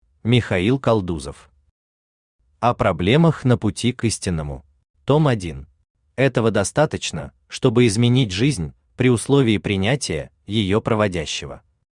Аудиокнига О проблемах на пути к истинному…
Автор Михаил Константинович Калдузов Читает аудиокнигу Авточтец ЛитРес.